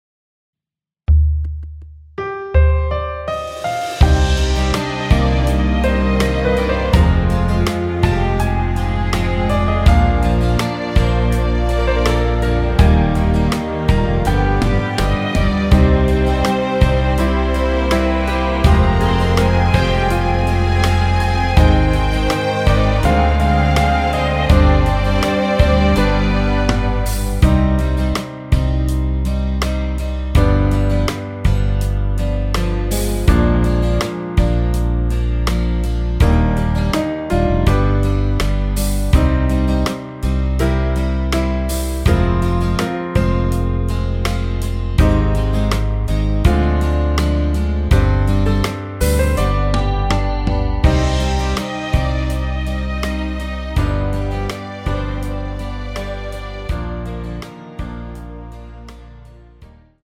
원키에서(-2)내린 MR입니다.
Cm
앞부분30초, 뒷부분30초씩 편집해서 올려 드리고 있습니다.
중간에 음이 끈어지고 다시 나오는 이유는